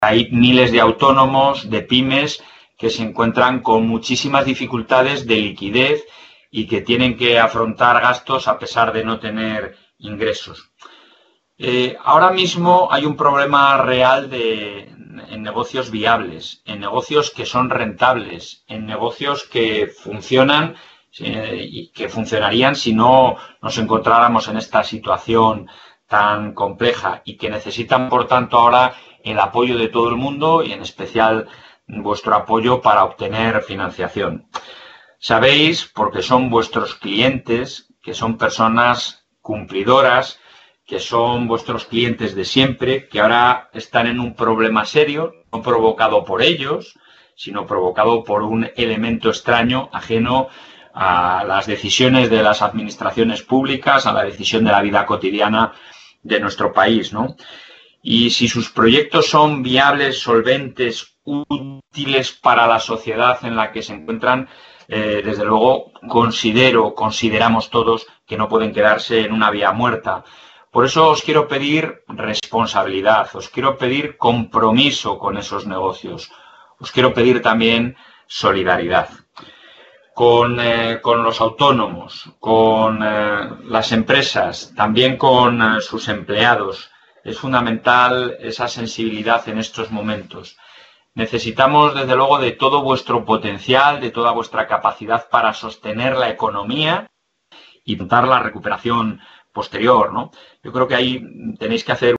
Audio videoconferencia.